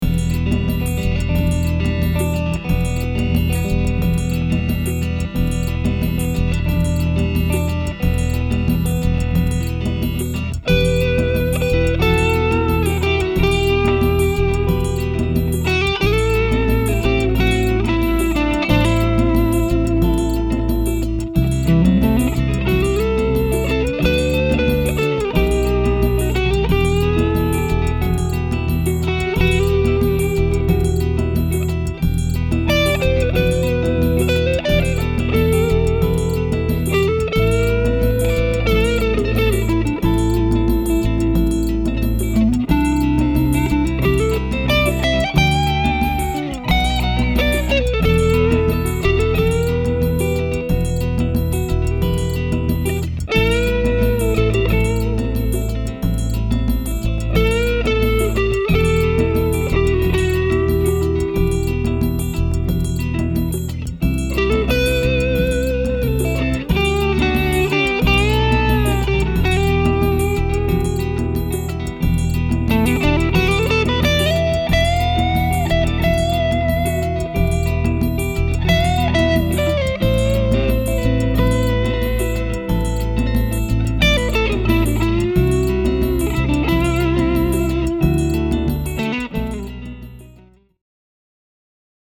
Since I was able to bring one into my studio, I decided to record a couple of clips to demonstrate how fat this guitar sounds…
The first clip features the Tele clean in the neck pickup for both rhythm and lead, though I dirtied up the lead part just a tad.
teleclean.mp3